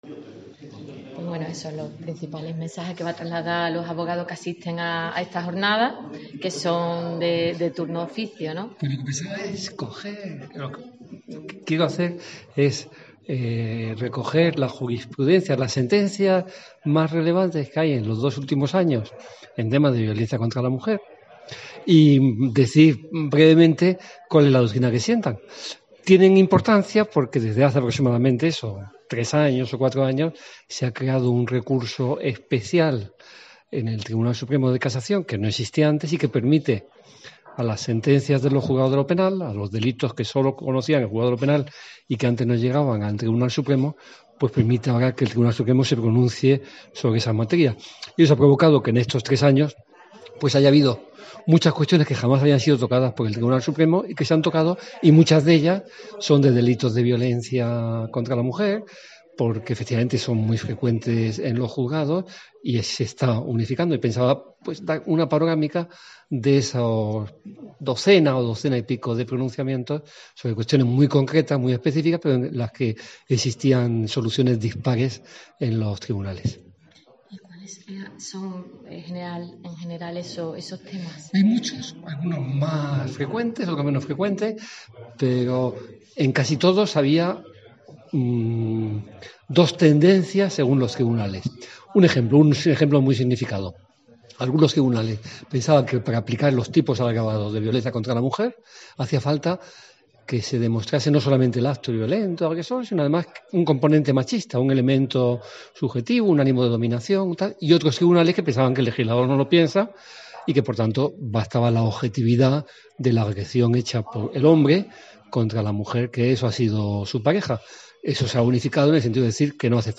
El Colegio de Abogados de Jerez –ICAB Jerez- celebró ayer viernes una importante jornada formativa sobre la violencia contra la mujer que contó con destacados ponentes como Antonio del Moral, Magistrado de Sala de lo Penal del Tribunal Supremo, y el magistrado Manuel María Estrella, presidente de la Audiencia Provincial de Cádiz.